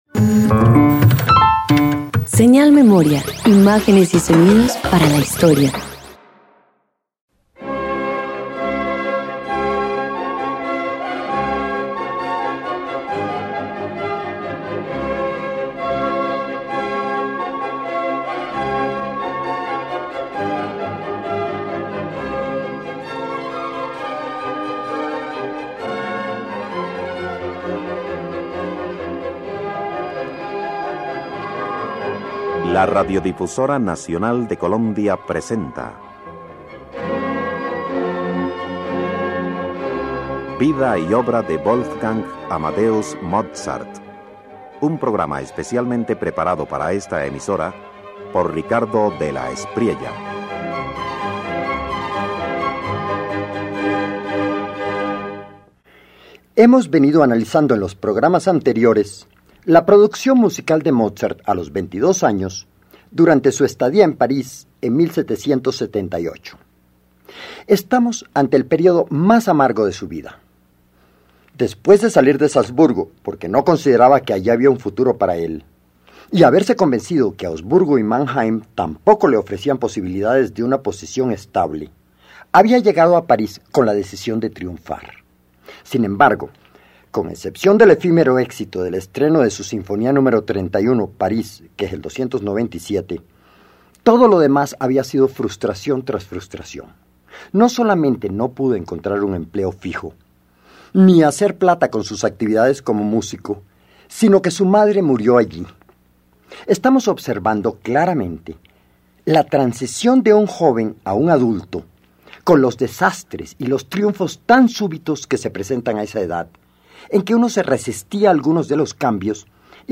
Tiempo después, aún en París, Wolfgang Amadeus Mozart compone el Capricho en do mayor K395 y la Sonata para piano en do mayor K330, obras cargadas de lirismo, sutileza y fuerte influencia francesa que revelan su transición artística.
Música para piano producida en París